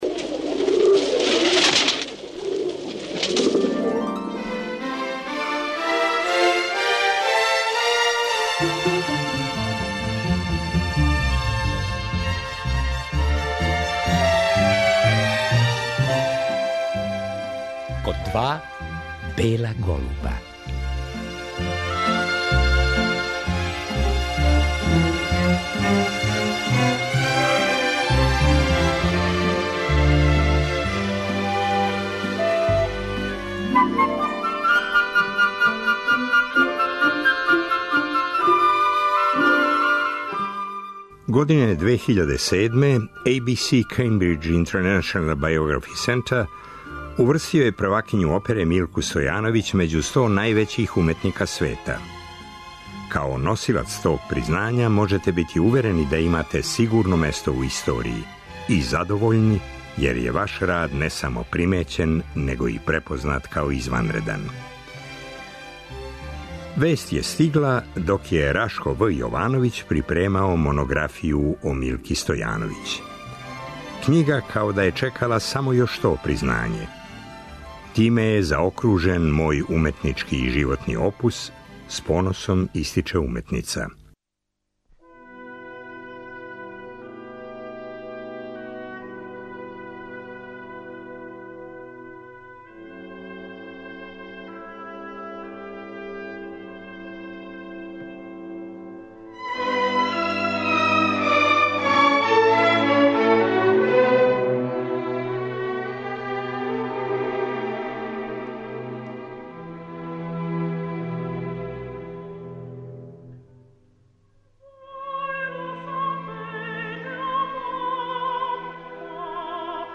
Поводом издања монографије “Милка Стојановић”, приређивач Рашко В. Јовановић, слушаћемо сећања оперске диве и арије из њеног богатог репертоара.